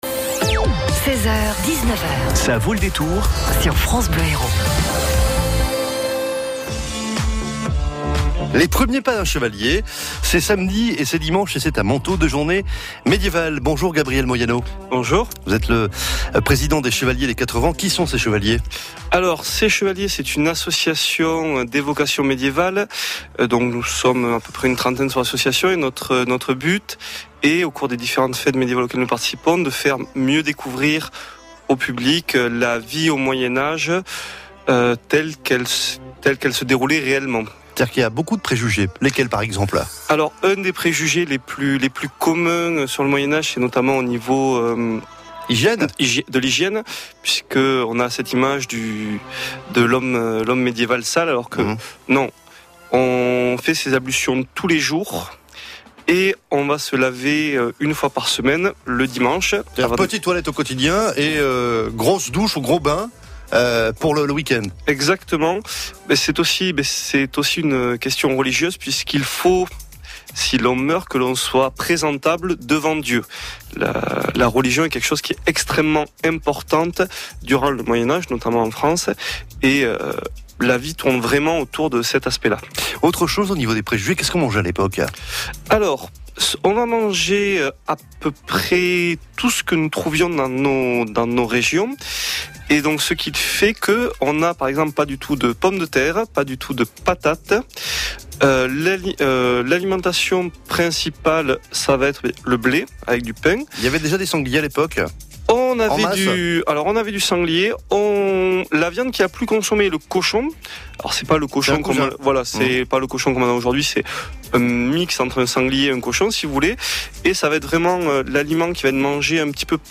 Ça vaut le détour, l’invité France Bleu Hérault